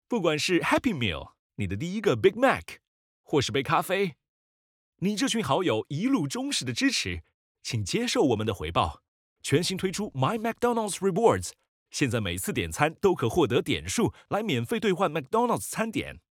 Kommerziell, Junge, Natürlich, Freundlich, Warm
Kommerziell